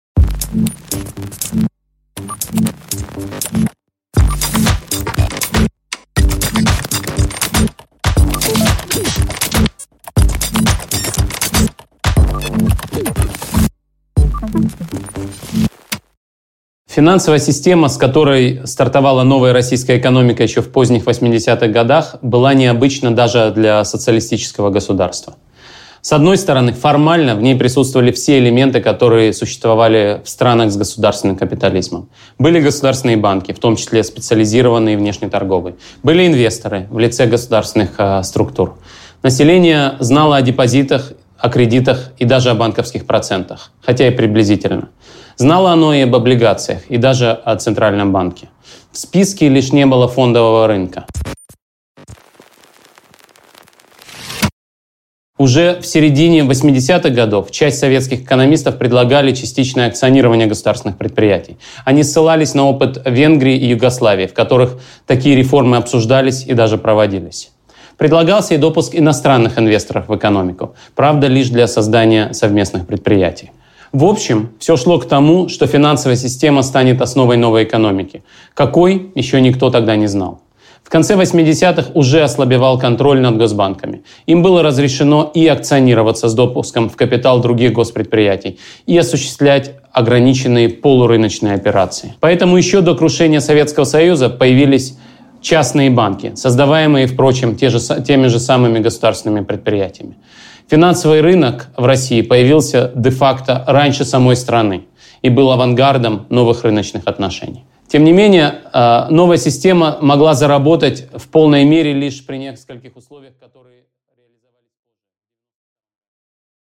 Аудиокнига Кредитная история: два взлета и три обвала российской финансовой системы | Библиотека аудиокниг